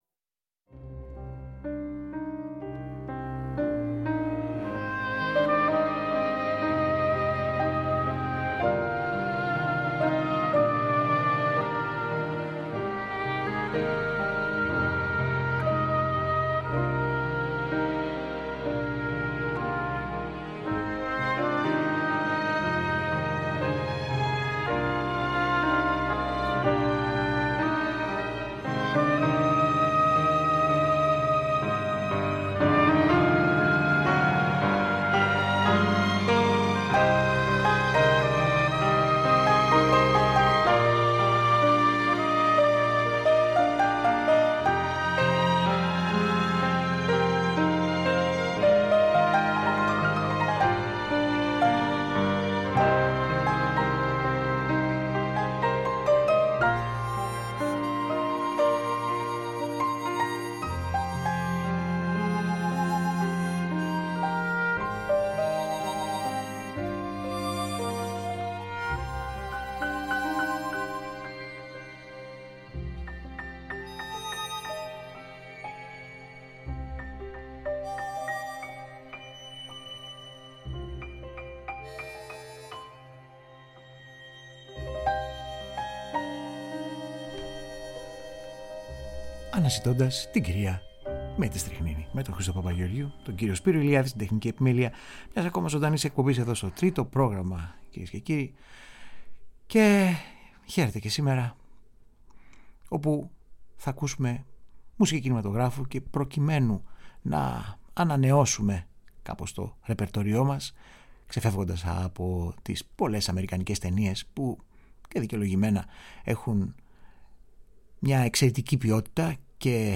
Ανεπανάληπτες μουσικές από ταινίες του Ευρωπαϊκού κινηματογράφου.